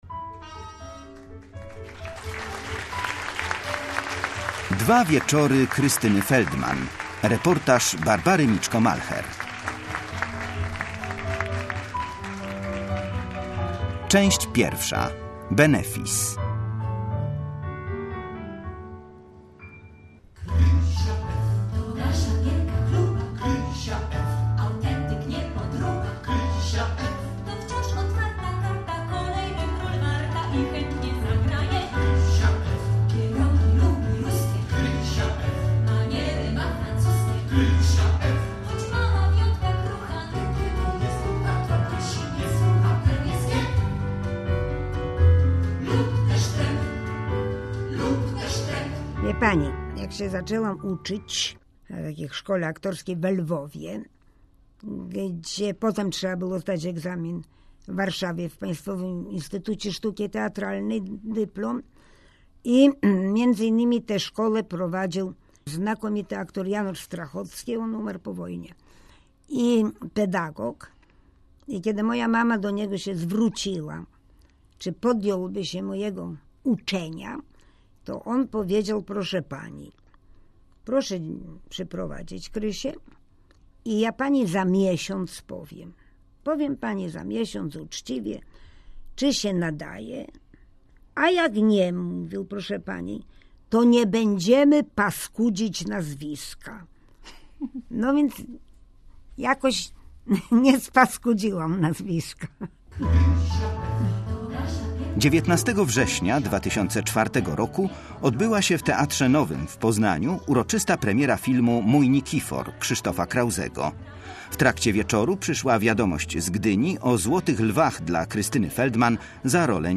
Dwa wieczory Krystyny Feldman - reportaż